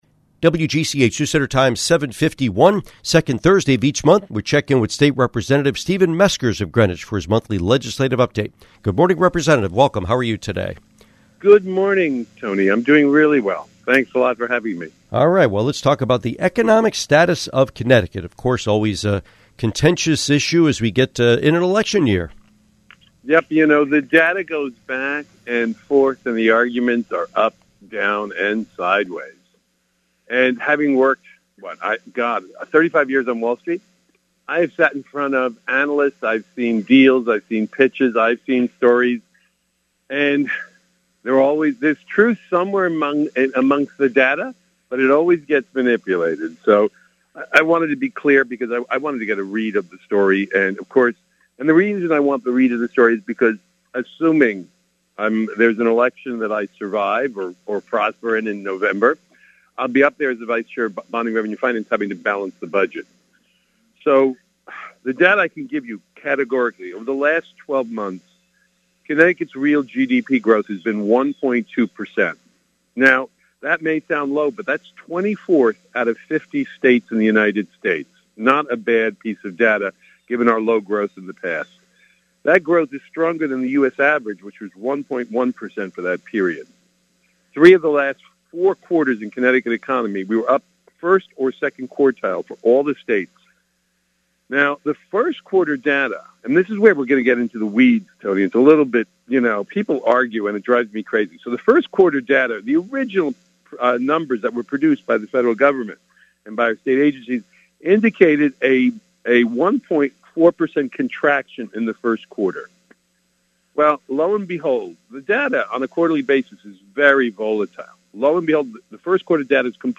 Interview with State Representative Kimberly Fiorello